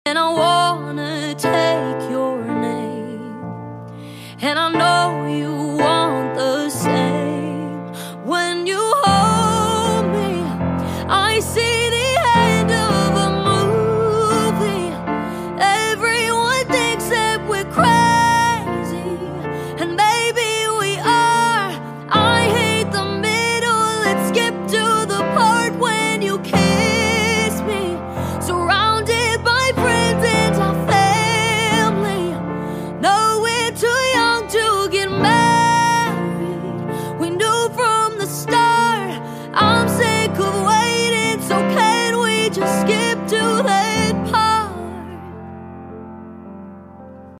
heartfelt ballad
powerful vocals